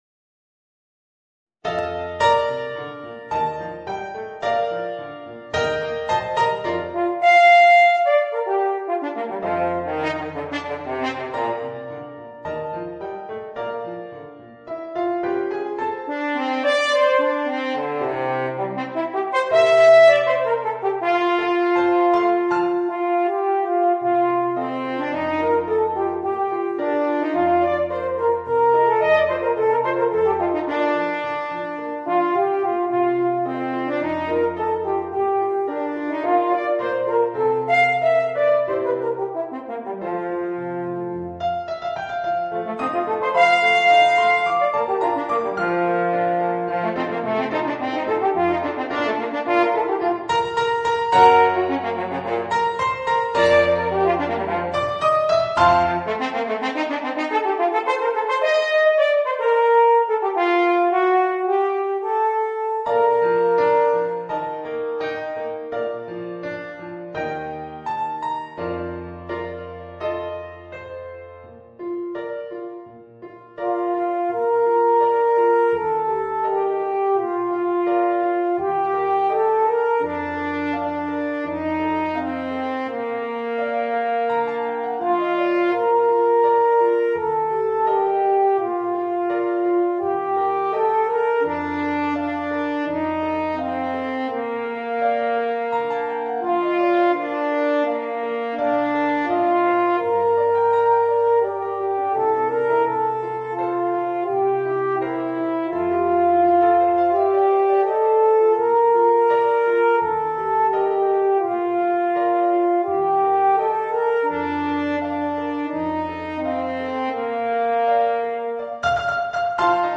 Voicing: Eb Horn and Piano